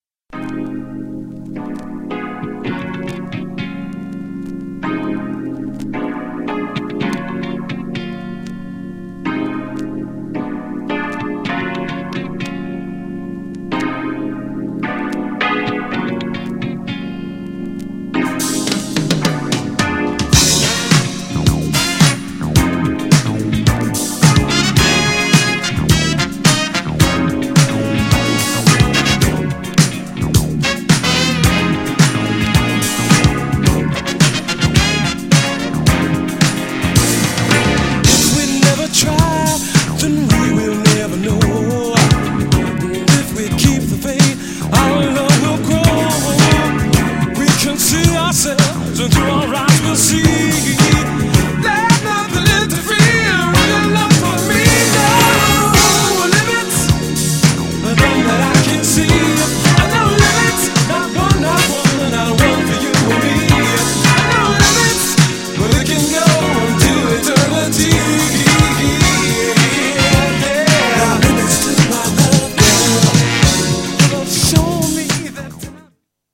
GENRE Dance Classic
BPM 101〜105BPM